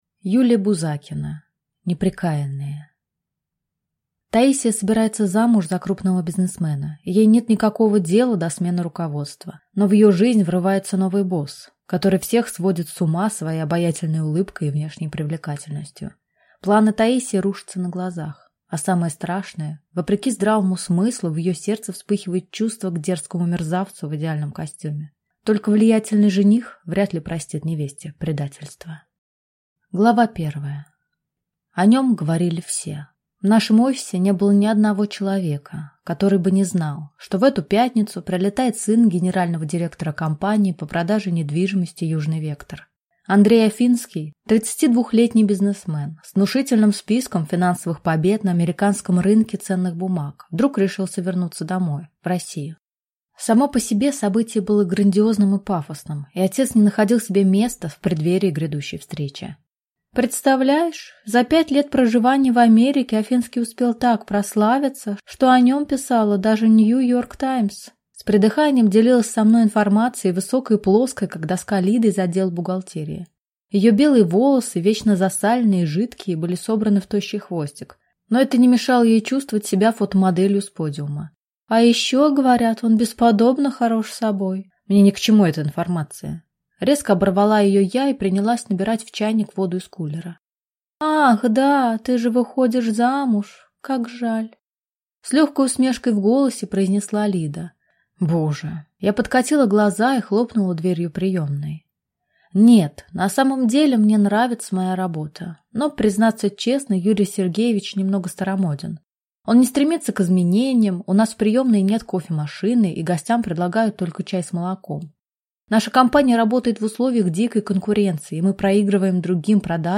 Аудиокнига Неприкаянные | Библиотека аудиокниг